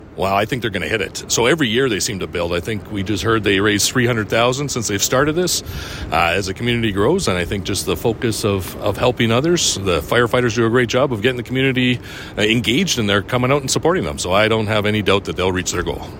Mayor Acker goes on to say that he believes that the firefighters will hit there goal in raising 20 thousand dollars this year with the help from the Spruce Grove community.